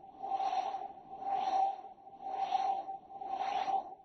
assembler.ogg